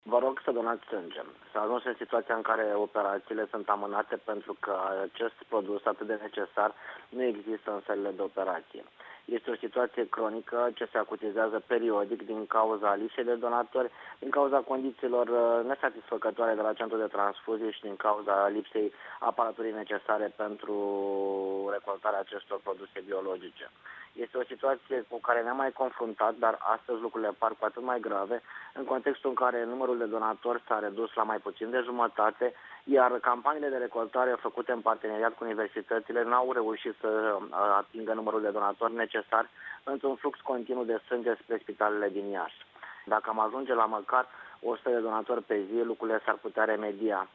Medicul Tudor Ciuhodaru a făcut cîteva precizări pe acest subiect: